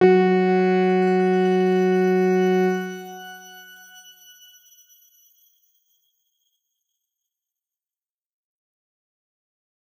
X_Grain-F#3-mf.wav